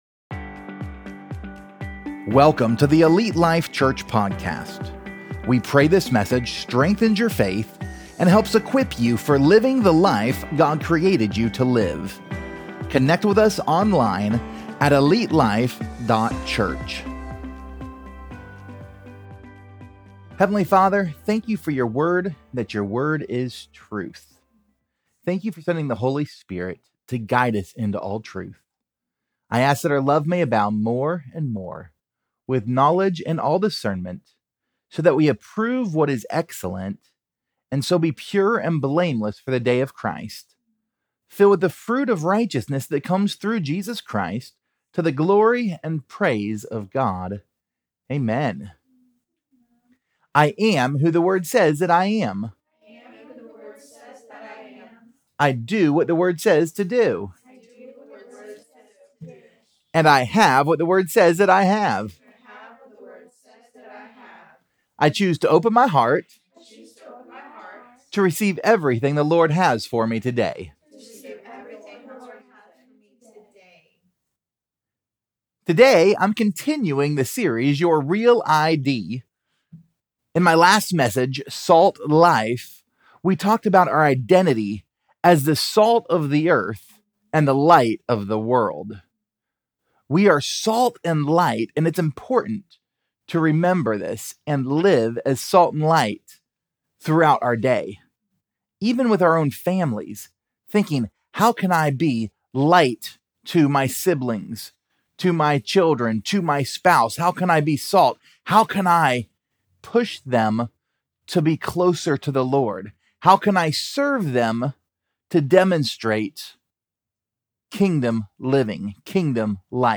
Pt 16: Blessed | Your REAL ID Sermon Series